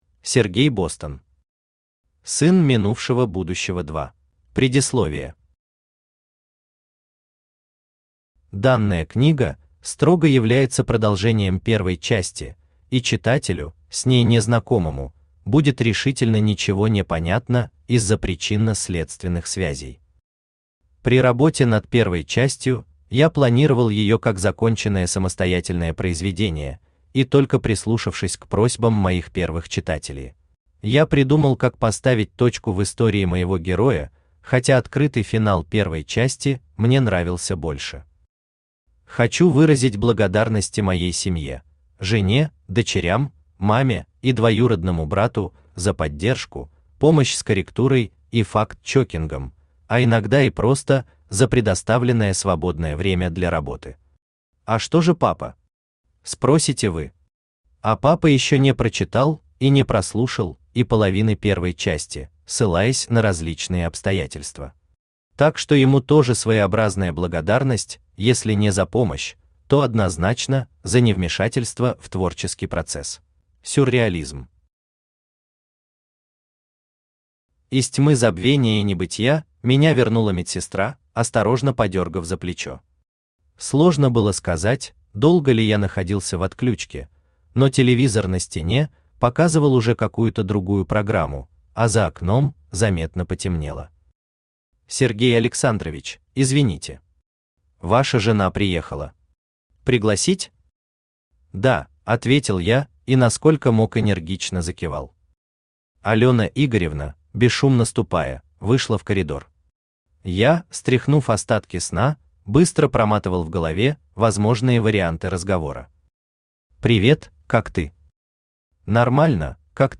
Аудиокнига Сын минувшего будущего 2 | Библиотека аудиокниг
Aудиокнига Сын минувшего будущего 2 Автор Сергей Бостон Читает аудиокнигу Авточтец ЛитРес.